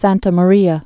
(săntə mə-rēə, säɴtä mä-rēä)